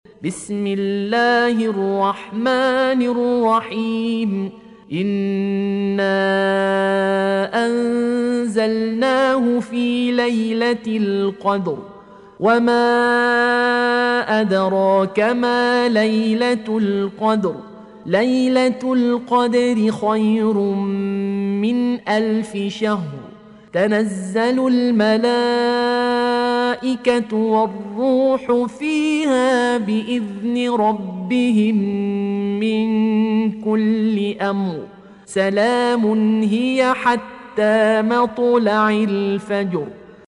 Audio Quran Tarteel Recitation
Surah Sequence تتابع السورة Download Surah حمّل السورة Reciting Murattalah Audio for 97. Surah Al-Qadr سورة القدر N.B *Surah Includes Al-Basmalah Reciters Sequents تتابع التلاوات Reciters Repeats تكرار التلاوات